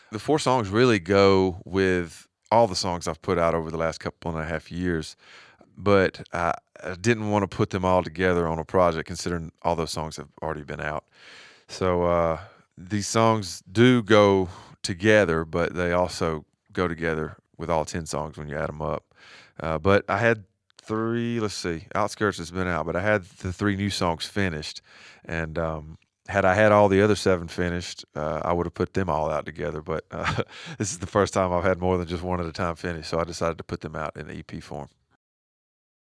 Sam Hunt explains why the four songs on his new EP go well together.